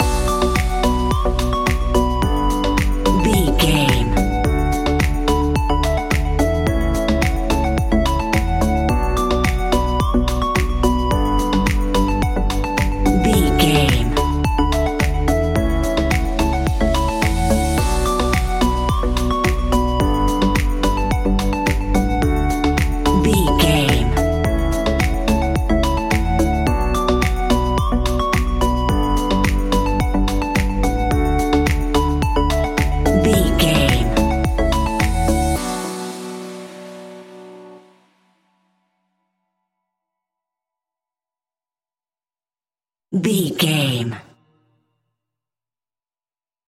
Aeolian/Minor
uplifting
energetic
bouncy
synthesiser
drum machine
electro house
synth bass